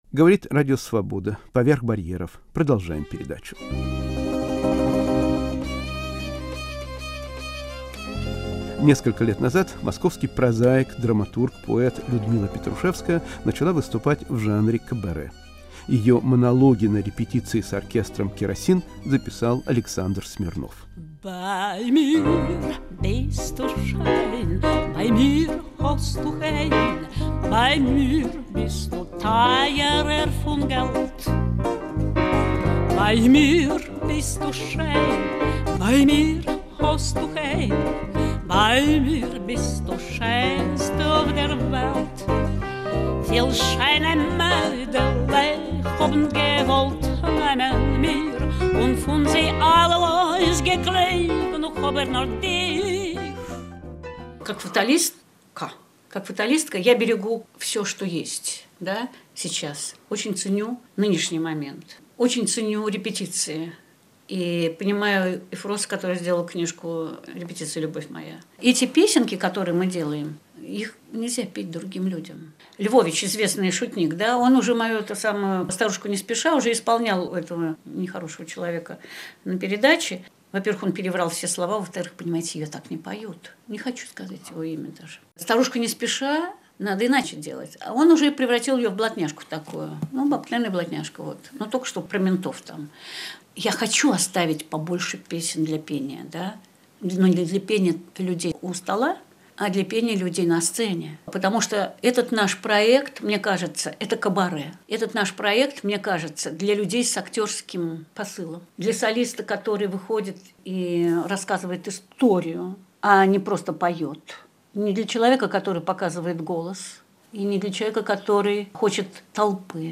Монологи и песни Людмилы Петрушевской